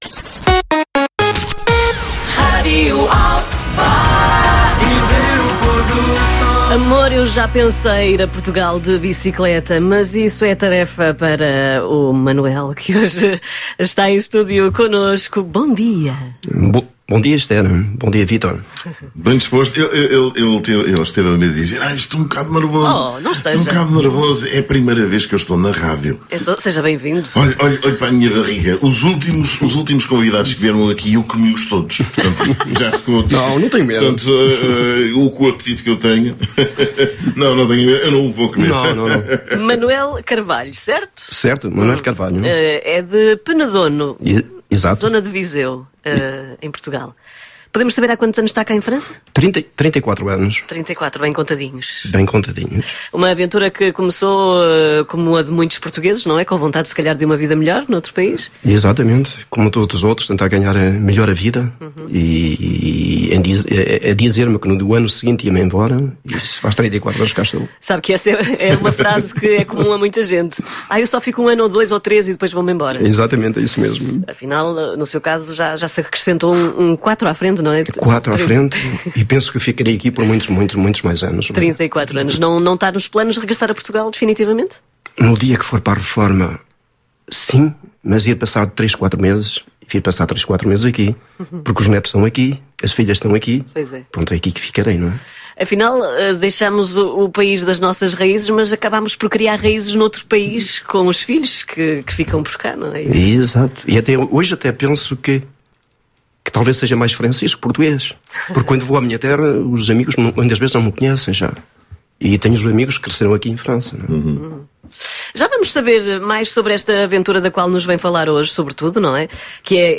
veio aos estúdios da Rádio Alfa falar desta aventura